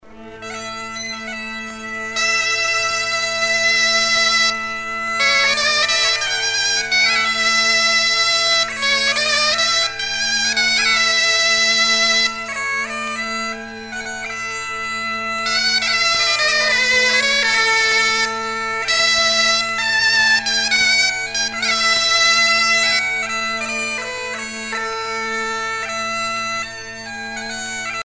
circonstance : fiançaille, noce
Pièce musicale éditée